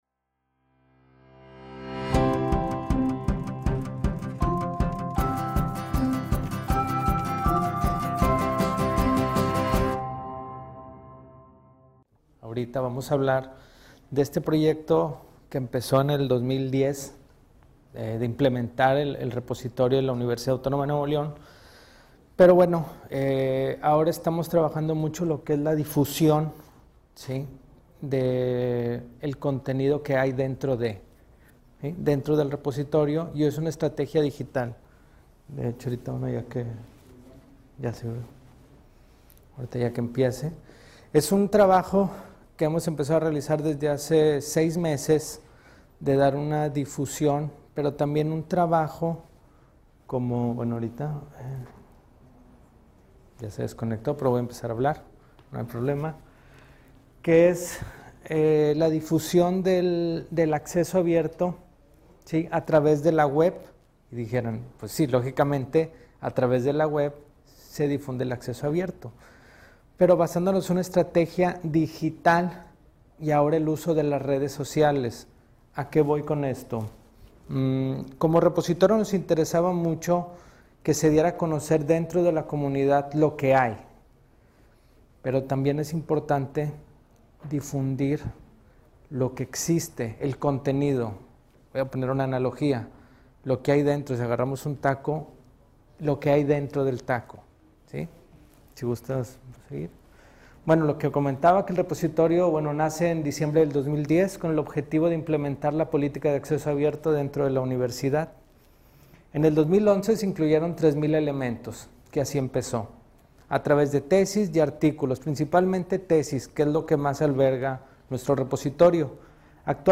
Audio de las conferencias